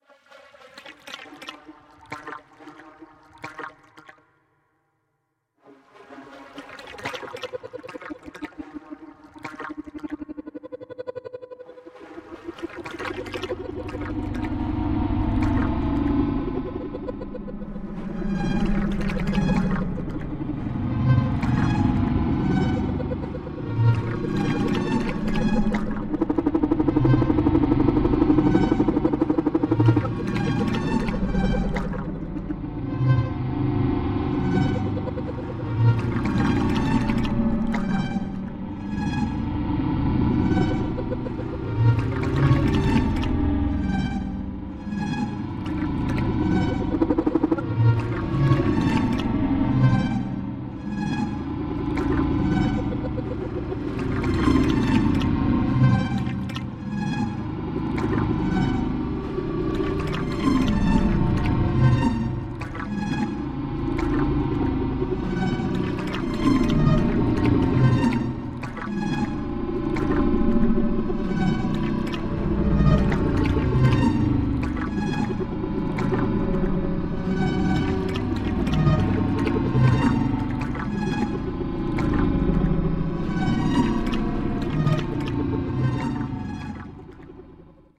MCCs - Musique Concrète for Csound
Examples of music generated with MCCs: